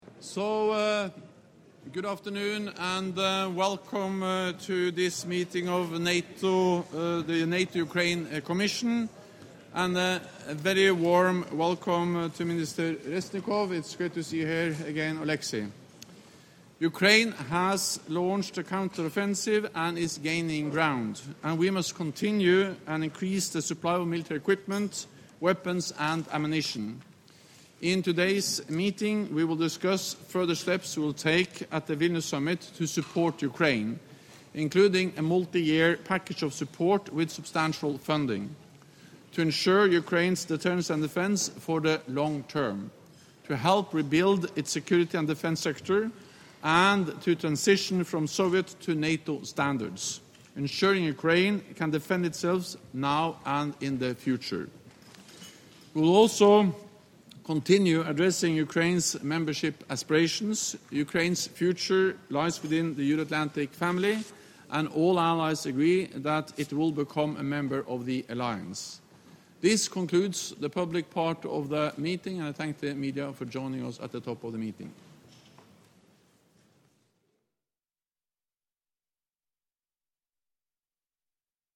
Public opening remarks by the Secretary General.